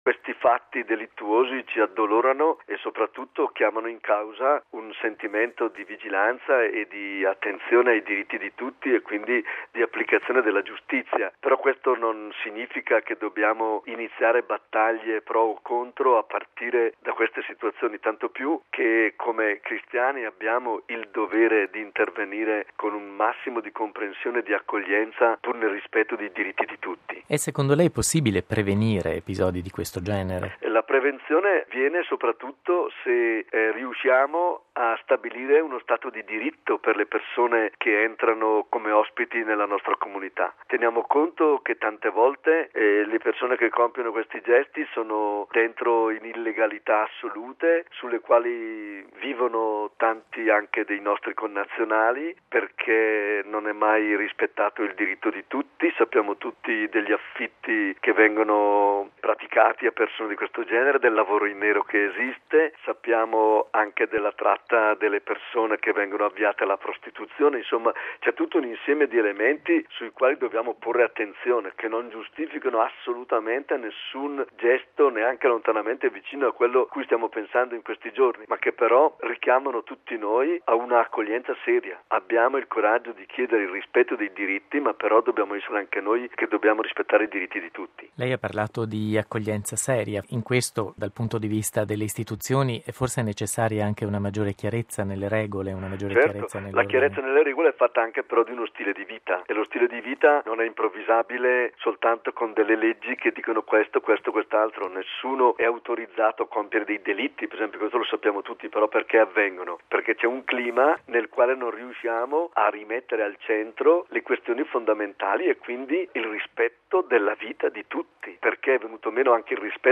mons. Domenico Sigalini, vescovo di Palestrina e segretario della Commissione per le Migrazioni della Conferenza episcopale italiana